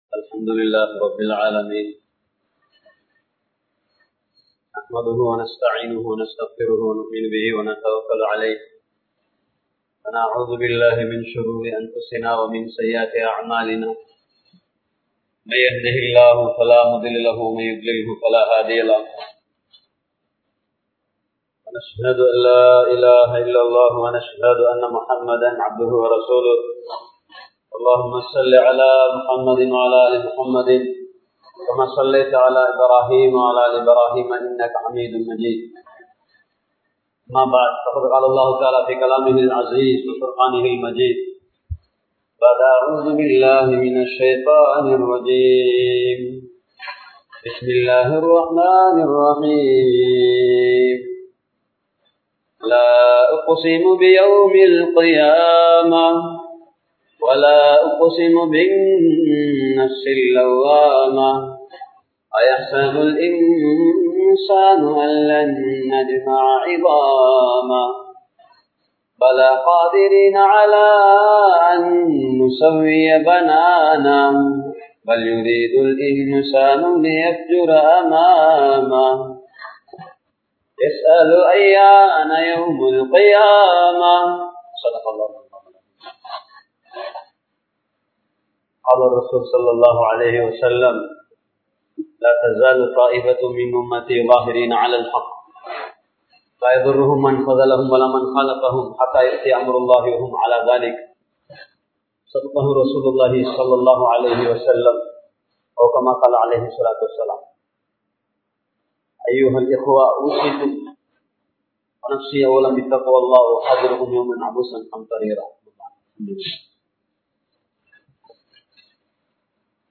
Padaiththavanin Sakthi (படைத்தவனின் சக்தி) | Audio Bayans | All Ceylon Muslim Youth Community | Addalaichenai
Masjidun Noor Jumua Masjidh